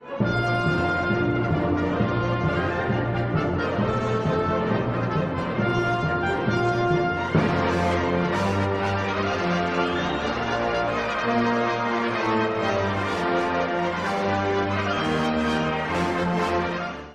古い音源なので聴きづらいかもしれません！（以下同様）
強烈なクライマックスに達して、スラヴ民族の勝利を確信するような力強さのうちに終わります。